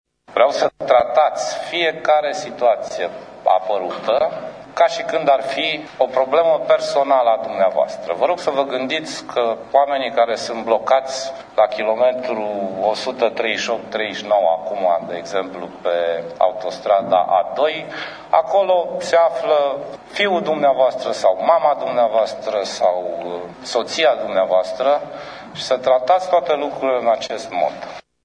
El le-a cerut miniştrilor să gestioneze fiecare situaţie apărută ca şi cum ar fi o problemă personală: